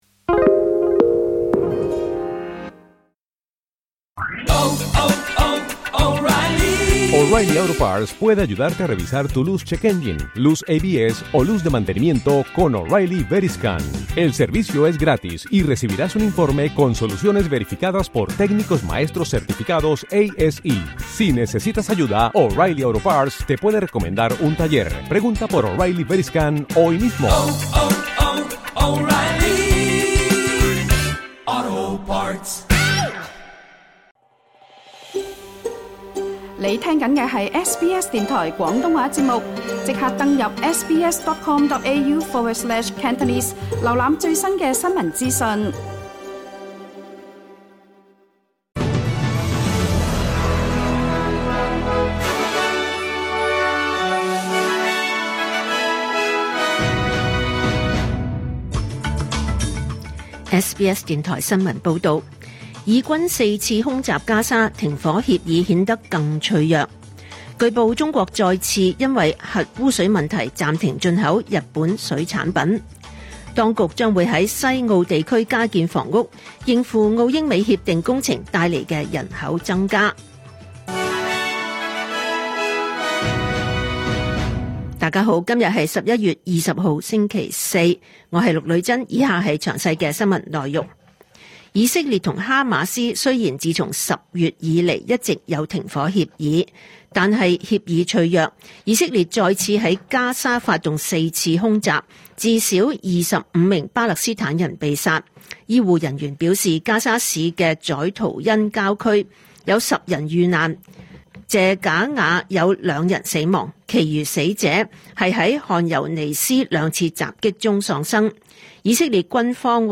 2025 年 11 月 20 日 SBS 廣東話節目詳盡早晨新聞報道。